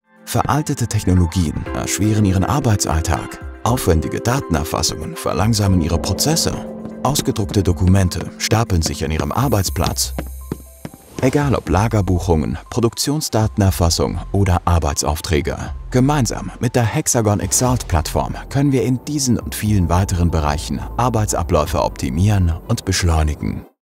Natürlich, Zuverlässig, Freundlich, Kommerziell, Warm
Erklärvideo
From his own studio, he offers high-end audio quality.